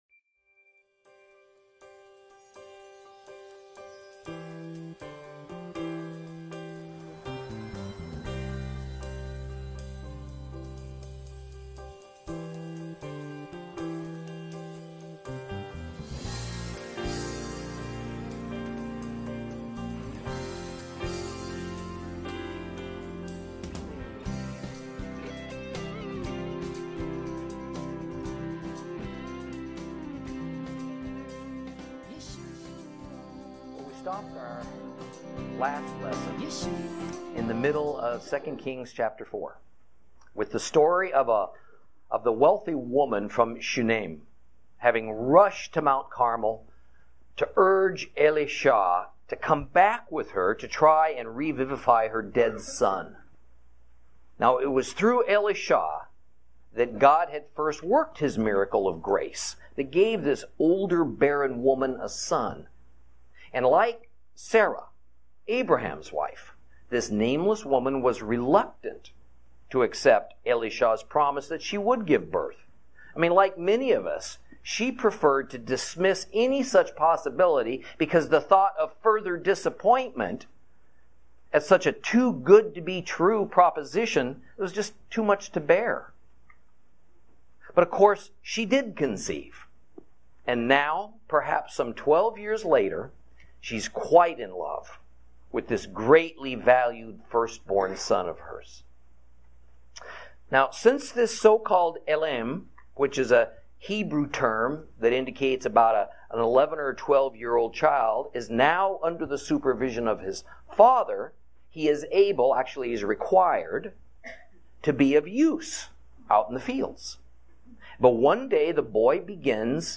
Lesson 6 Ch4 Ch5 - Torah Class